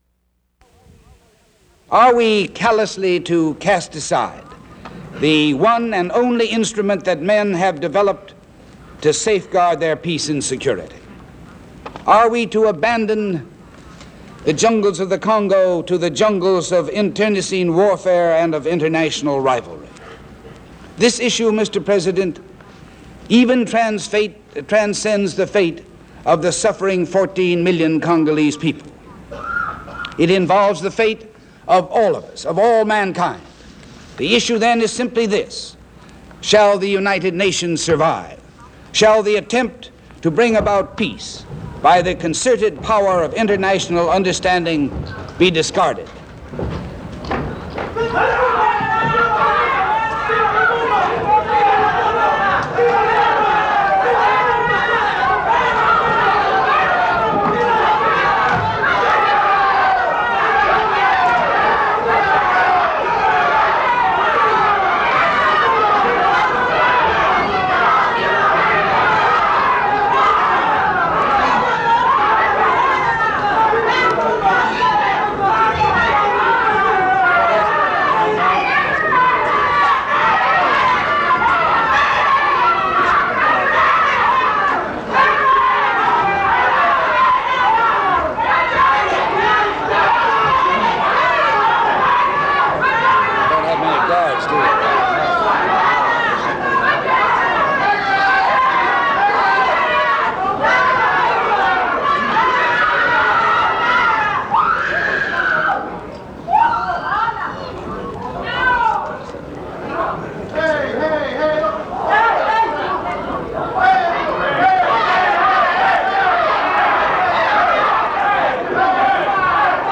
U.S. Ambassador to the United Nations Adlai Stevenson speaks about the war in Congo